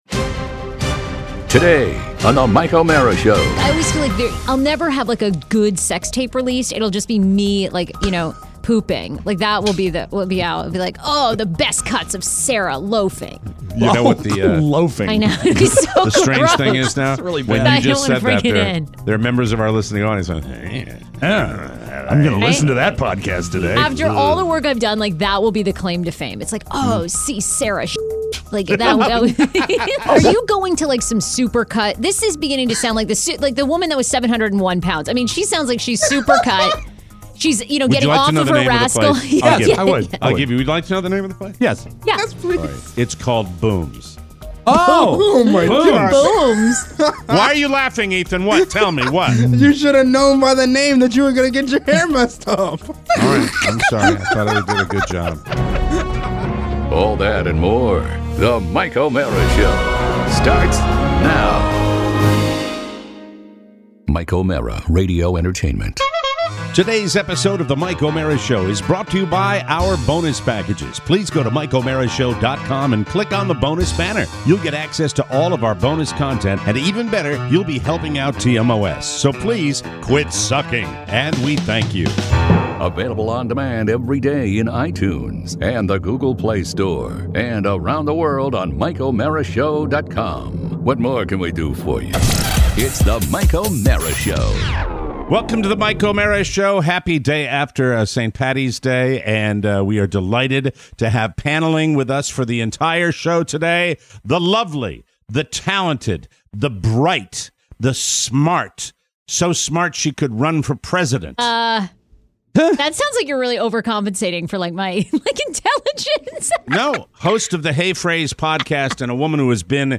joins us in studio with a wedding update! Plus, a quiet St. Patty’s Day, a visit to the Yak Shack… and thoughts on barbers.